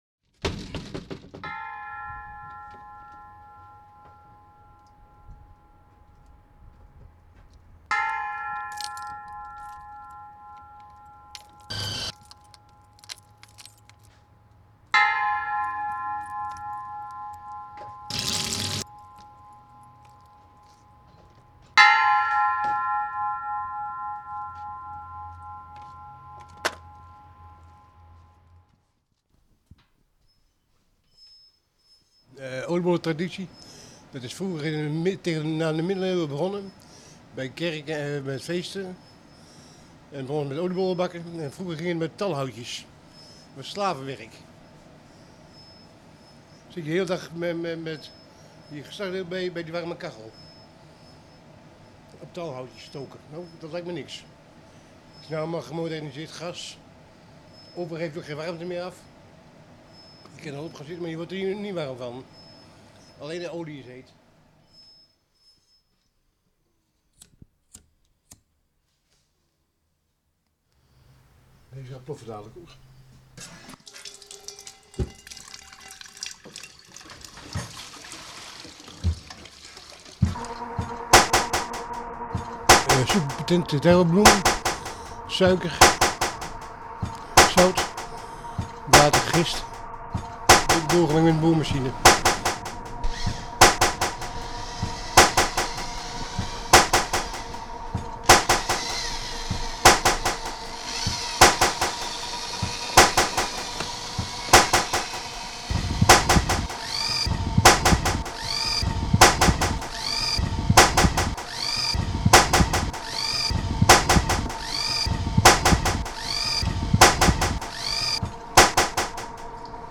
Audio portrait about a traditional oliebol baker for Edge 2.0 radio.